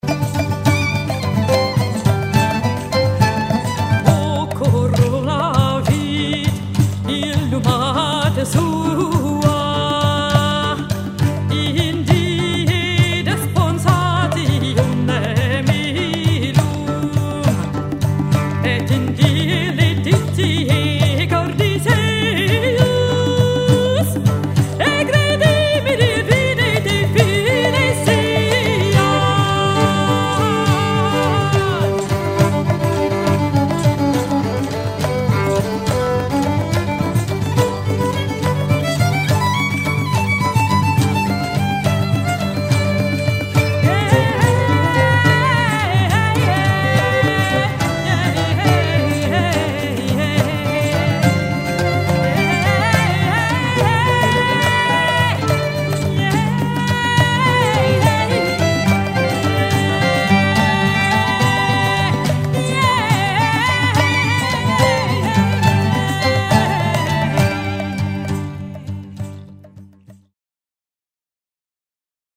Basse
Percussions
Violon